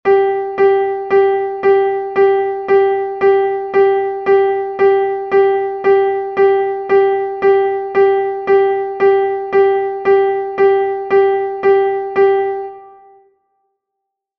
moderato.mp3